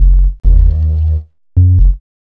3708L B-LOOP.wav